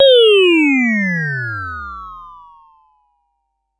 Index of /musicradar/essential-drumkit-samples/Vermona DRM1 Kit
Vermona Fx 04.wav